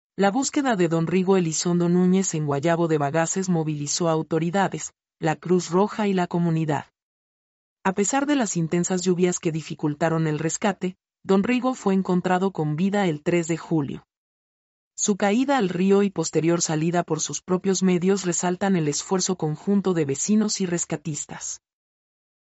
mp3-output-ttsfreedotcom-13-1.mp3